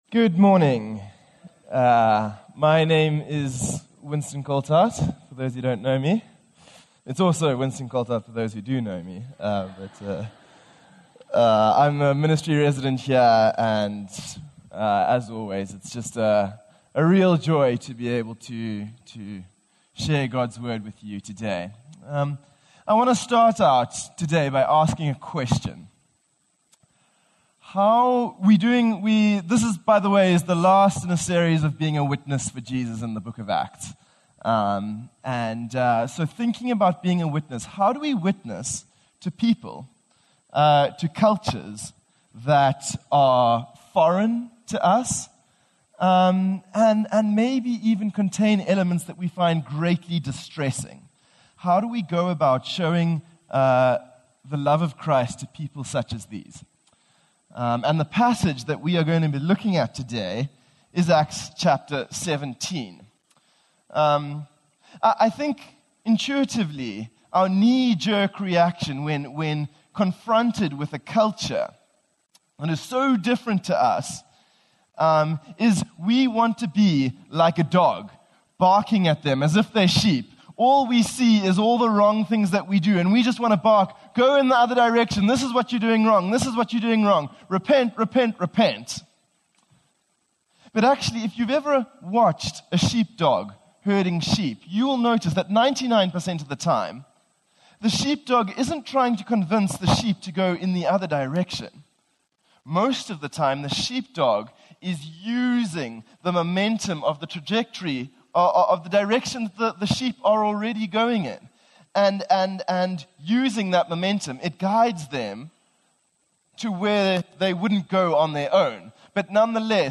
That They Might Seek God - Sermon - Woodbine